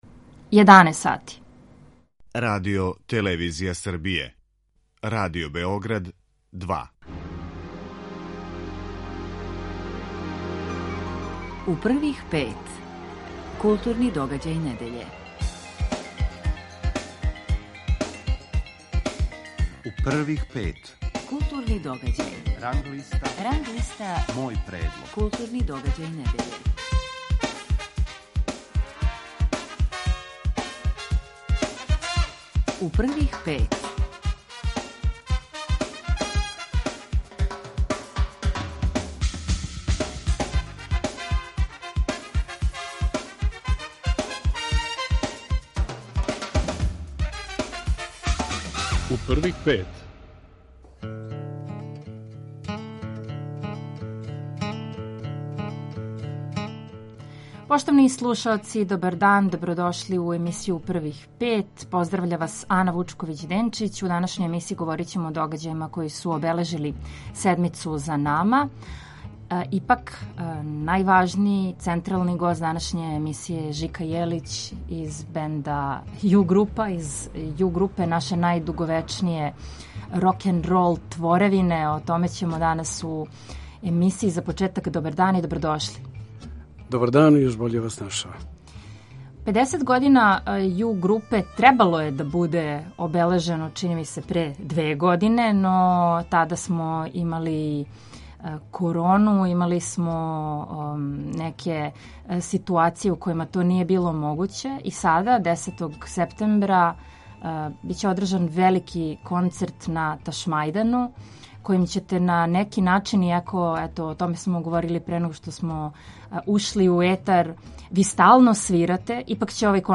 Неке од њих слушаћемо и данас, када је гост емисије У првих пет Живорад Жика Јелић . Разговараћемо о узбуђењу и жилавости рокенрола, о континуитету живота у рокенролу, о песмама, братству и музици.